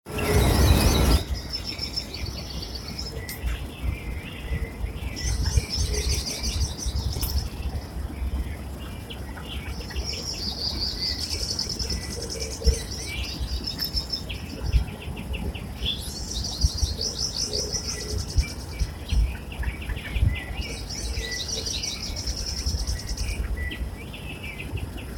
Southern House Wren (Troglodytes musculus)
English Name: Southern House Wren
Life Stage: Adult
Detailed location: Eco Área Avellaneda
Condition: Wild
Certainty: Photographed, Recorded vocal